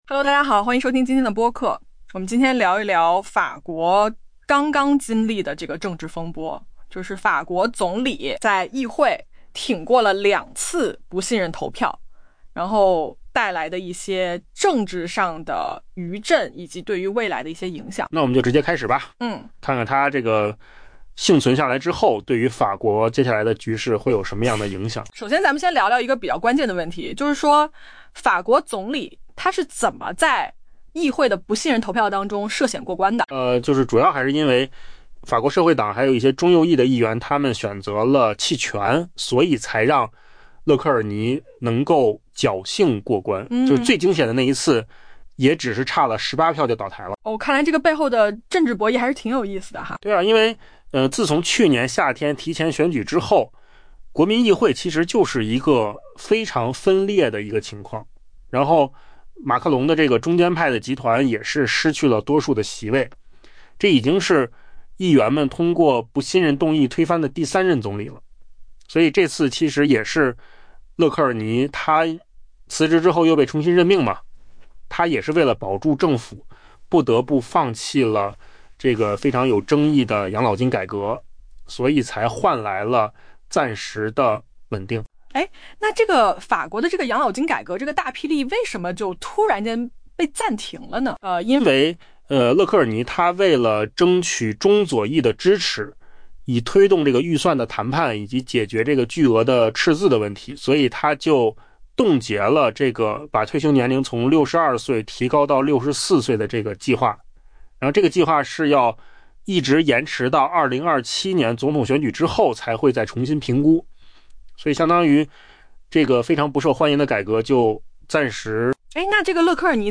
AI 播客：换个方式听新闻 下载 mp3 音频由扣子空间生成 法国总理勒科尔尼周四在议会的两次不信任投票中幸存下来，为他赢得了脆弱的喘息之机，并为通过 2026 年预算创造了机会。